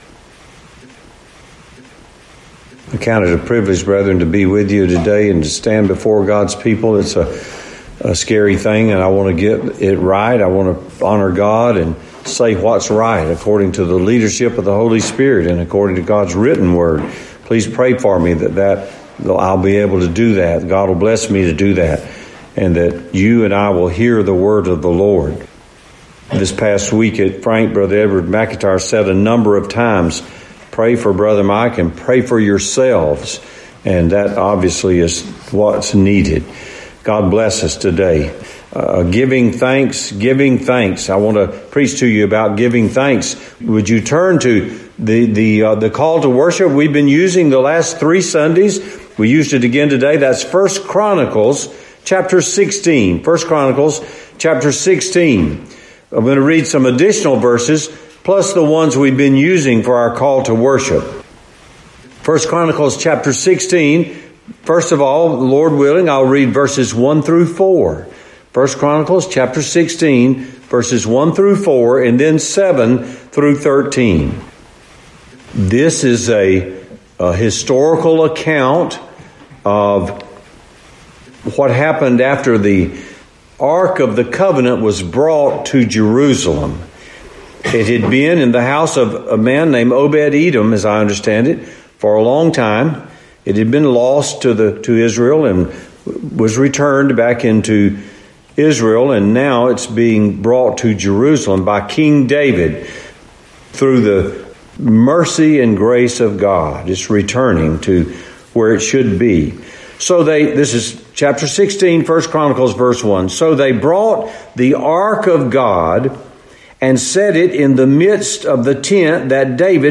Luke 10:17-22, Thankful For God’s Sovereignty Nov 17 In: Sermon by Speaker